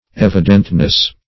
Evidentness \Ev"i*dent*ness\, n.